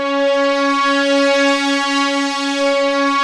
P.5 C#5 7.wav